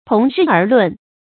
同日而論 注音： ㄊㄨㄙˊ ㄖㄧˋ ㄦˊ ㄌㄨㄣˋ 讀音讀法： 意思解釋： 猶言相提并論。